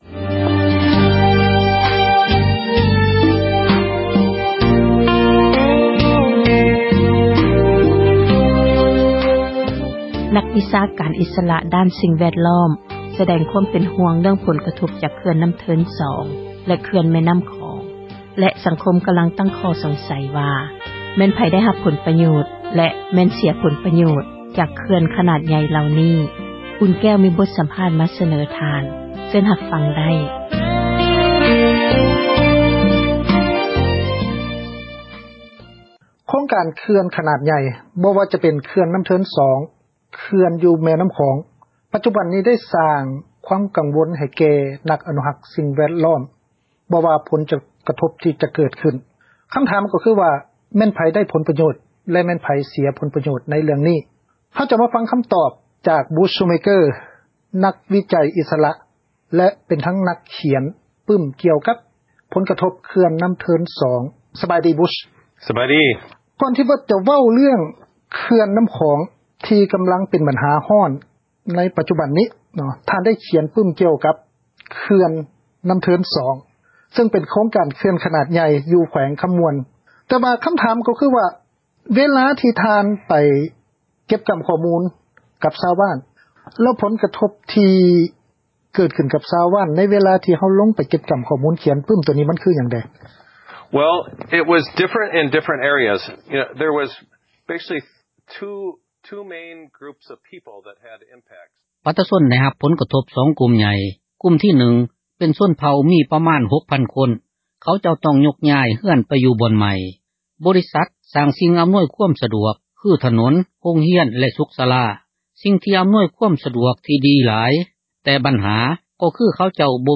ມີບົດສັມພາດ ມາສເນີ ທ່ານ.